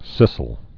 (sĭsəl, -īl)